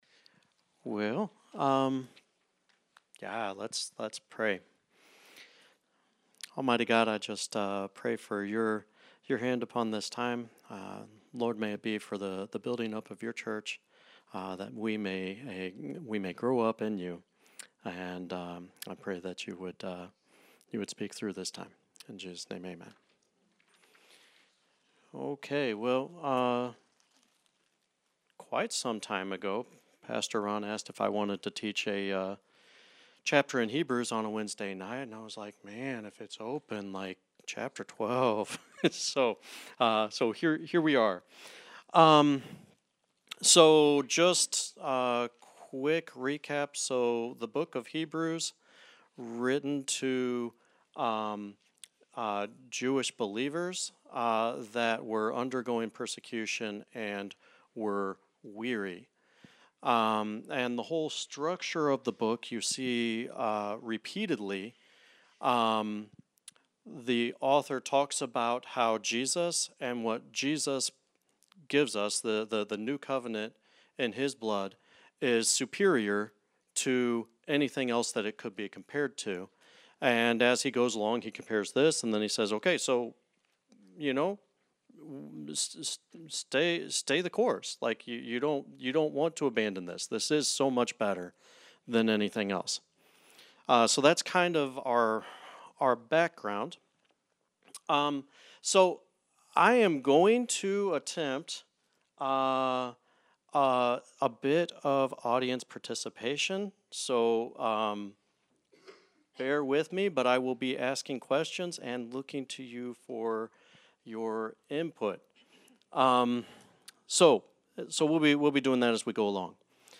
Series: Guest Speakers, Study of Hebrews